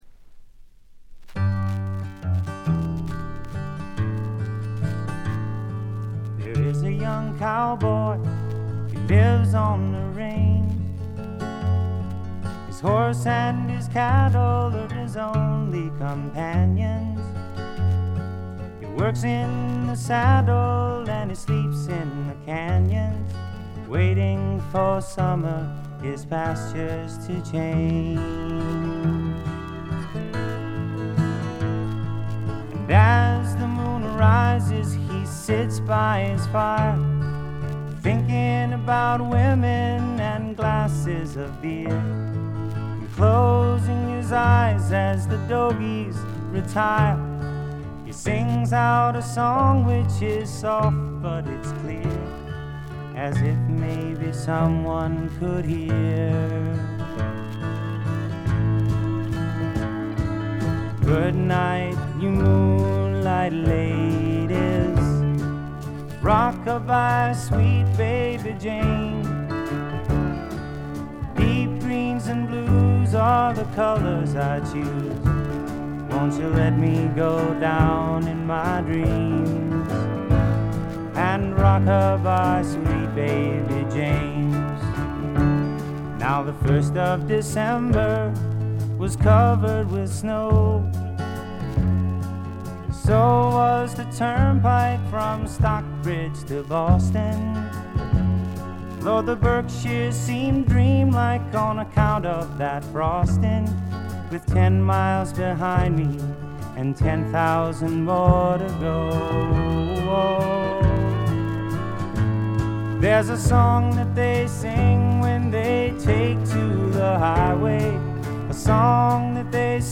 バックグラウンドノイズ、ところどころでチリプチ。A1冒頭チリつき。
シンガー・ソングライター基本盤。
試聴曲は現品からの取り込み音源です。
Recorded at Sunset Sound, December '69